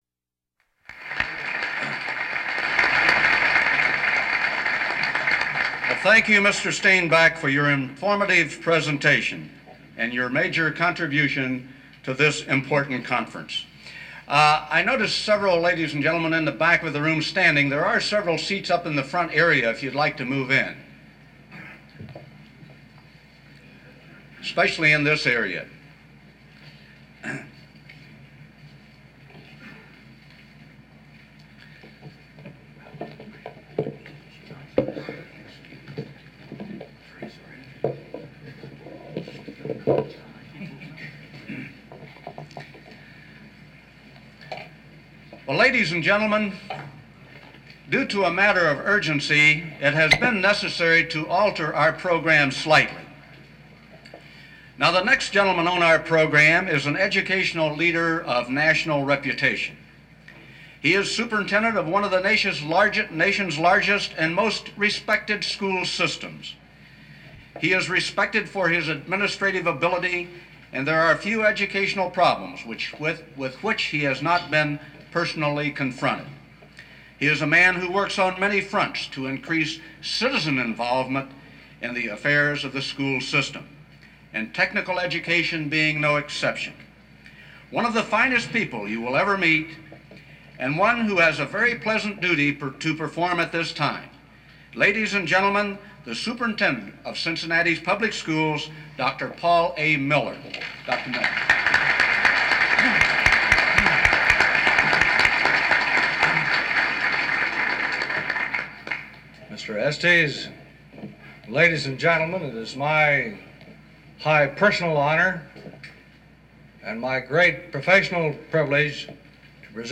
We have the audio of Gov. Rhodes’ address to the conference luncheon! Converted to a digital surrogate from the original reel, for your listening pleasure.